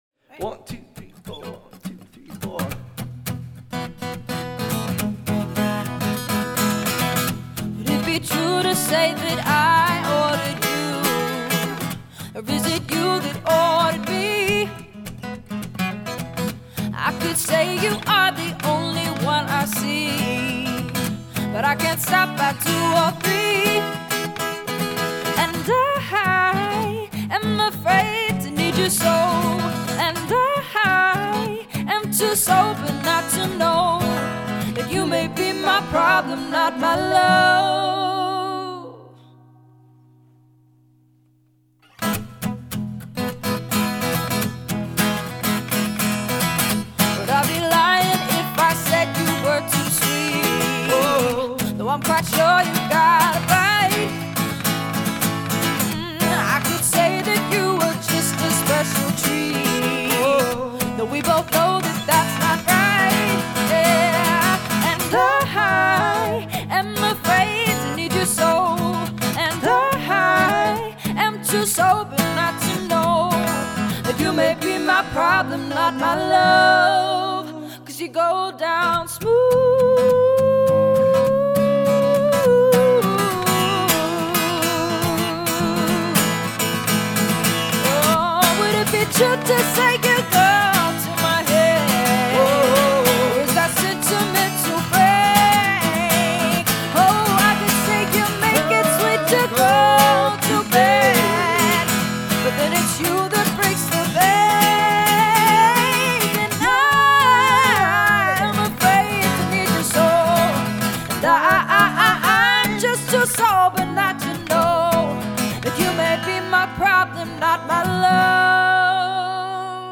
Joining forces as an acoustic duo